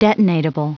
Prononciation du mot detonatable en anglais (fichier audio)